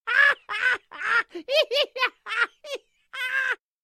comedy_male_cartoon_character_laughing